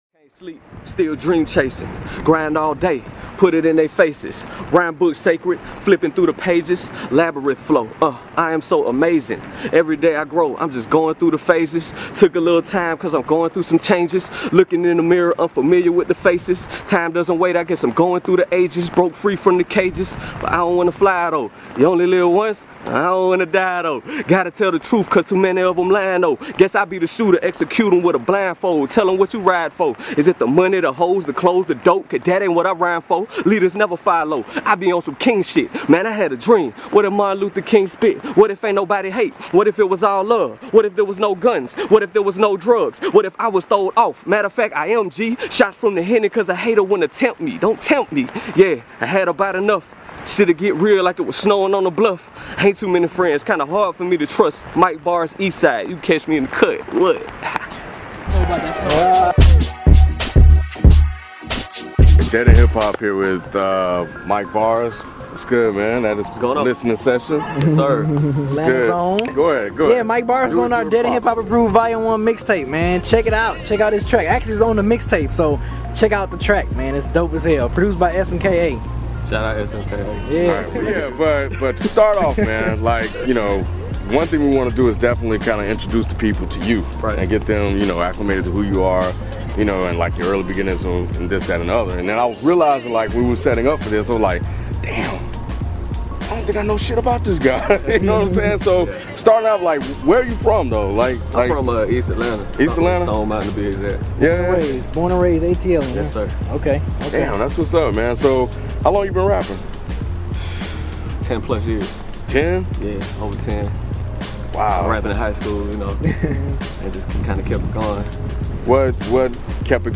DEHH Indie Interviews